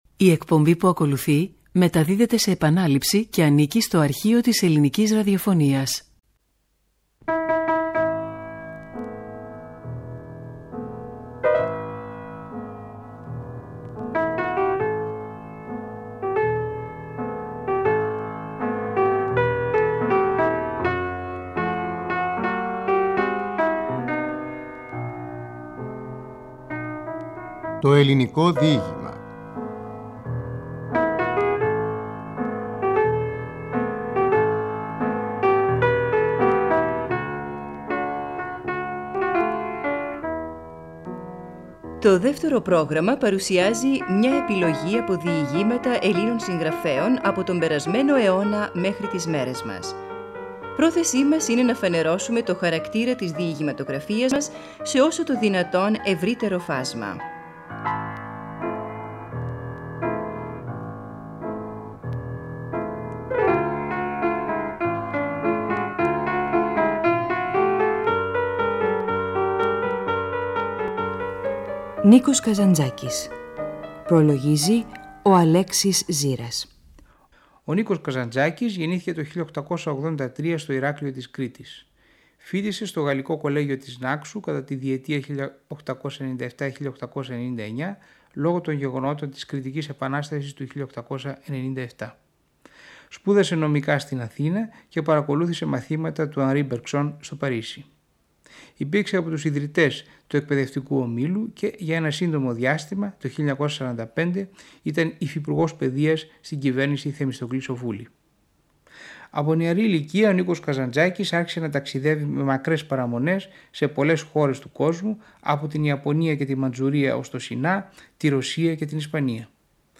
Ο Στέφανος Ληναίος διαβάζει απόσπασμα από το ταξιδιωτικό έργο «Ιαπωνία – Κίνα» .